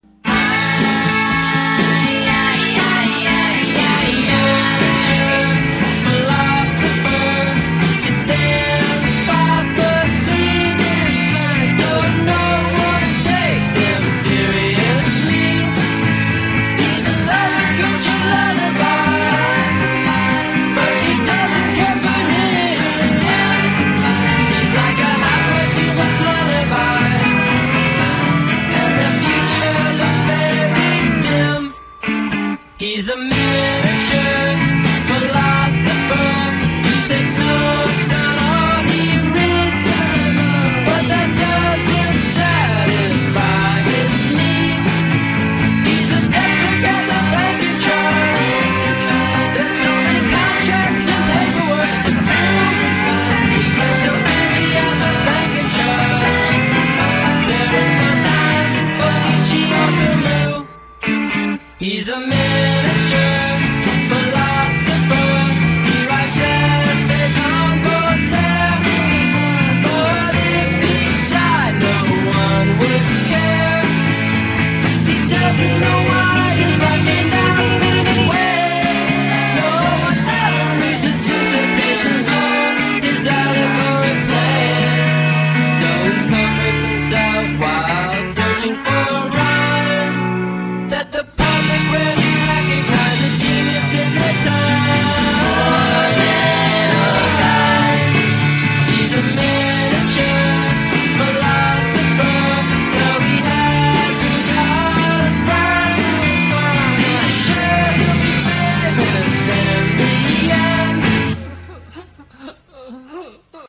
The album is brimming with thick layers of pop psychedelica.